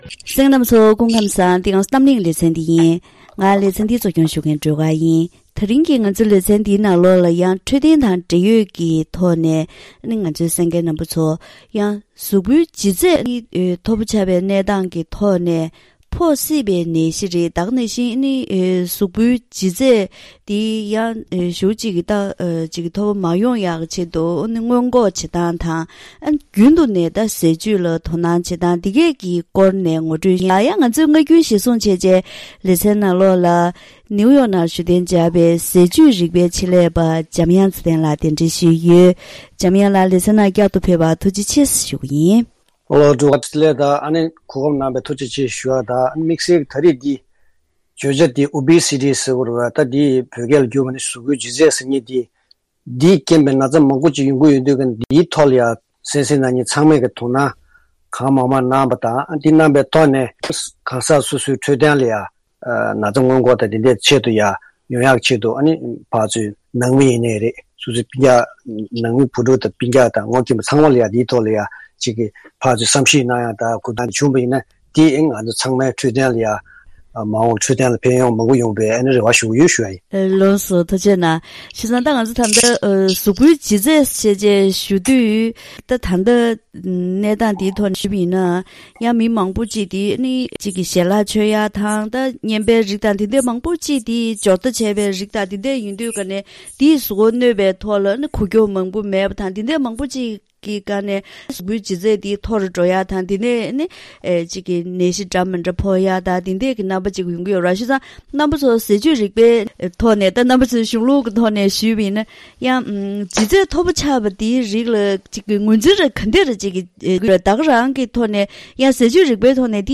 ད་རིང་གི་གཏམ་གླེང་ཞལ་པར་ལེ་ཚན་ནང་གཟུགས་པོའི་ལྗིད་ཚད་མཐོ་པོ་ཆགས་པའི་གནས་སྟངས་འོག་ནད་གཞི་མང་པོ་ཞིག་ཕོག་སླ་བས། ཉིན་རེའི་འཚོ་བའི་ནང་ཟས་སྤྱོད་བསྟེན་སྟངས་དང་། སྔོན་འགོག་ཡོང་ཐབས་སོགས་ཀྱི་སྐོར་ལ་ཟས་བཅུད་རིག་པའི་ཆེད་ལས་པ་དང་ལྷན་དུ་བཀའ་མོལ་ཞུས་པ་ཞིག་གསན་རོགས་གནང་།